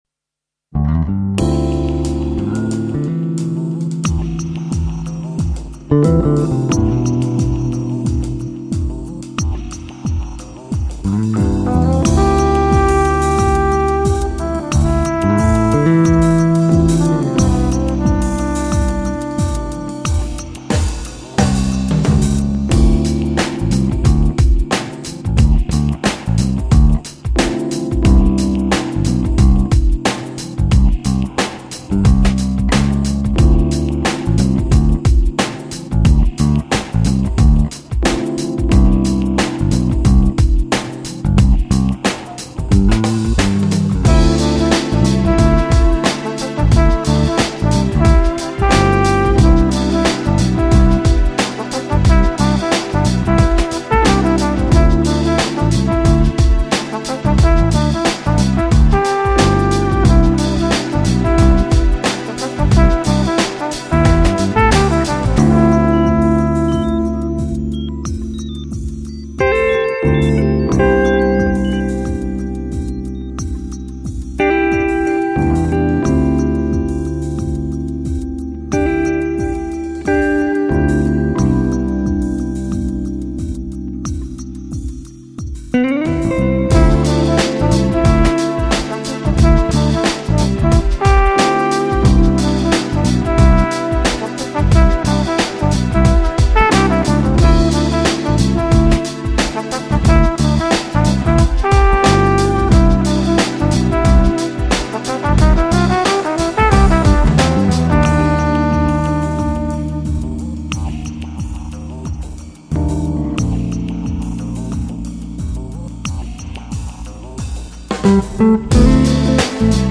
Lounge, Chill Out, Downtempo Год издания